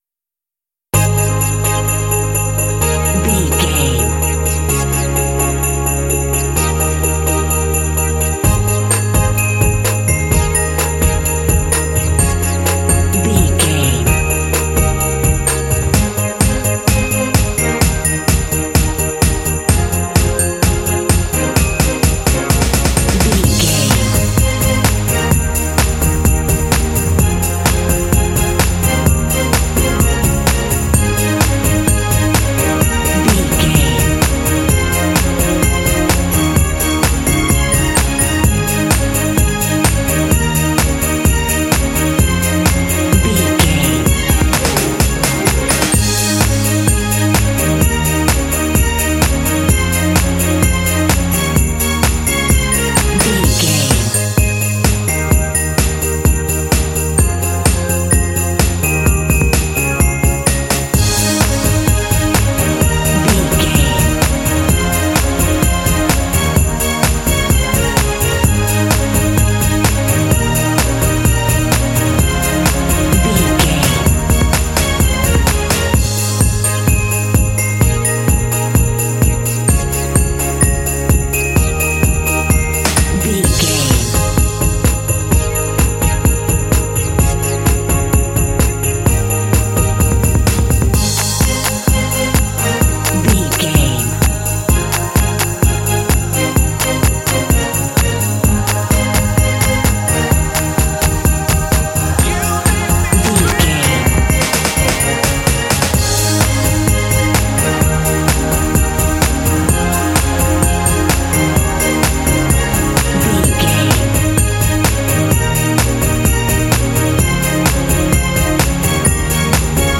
Ionian/Major
A♭
happy
uplifting
bouncy
festive
synthesiser
drums
strings
contemporary underscore